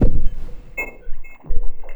Abstract Rhythm 21.wav